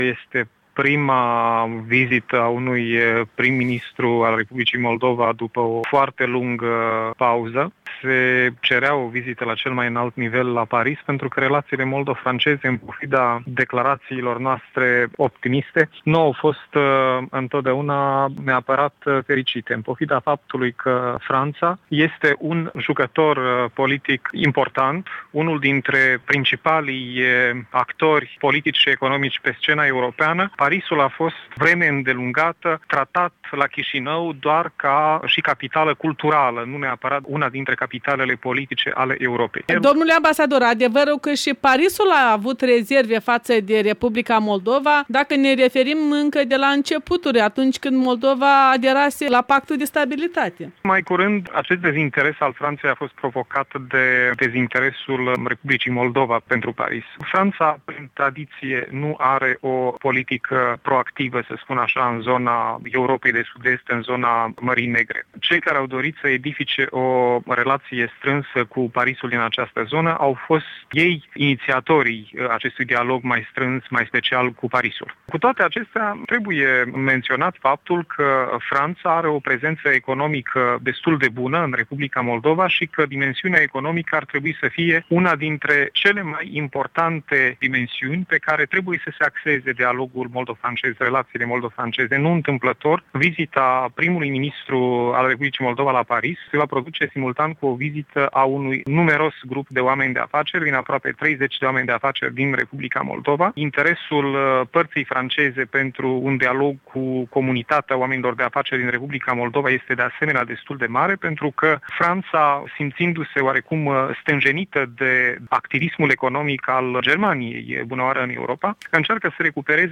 Interviu cu ambasadorul Oleg Serebrian.
In ajunul vizitei premierului Filat la Paris - ambasadorul Oleg Serebrian răspunde întrebărilor EL